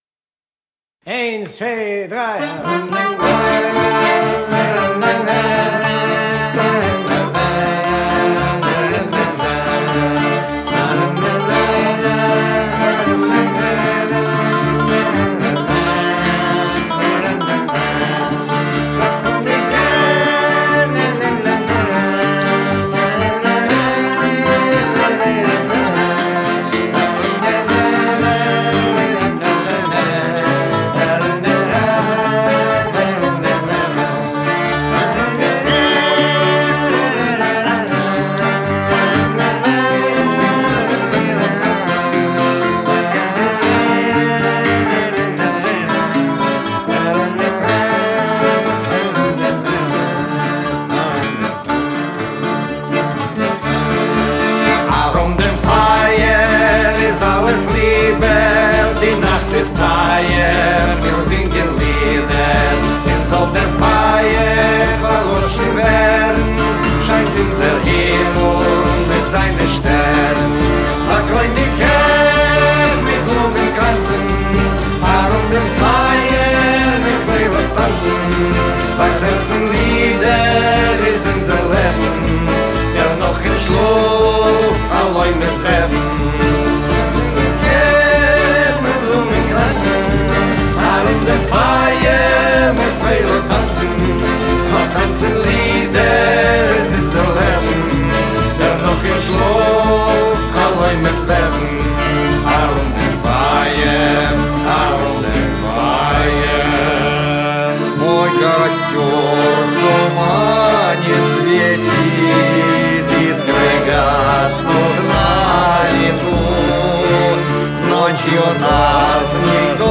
Genre: Folk